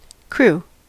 Ääntäminen
US Tuntematon aksentti: IPA : /kɹuː/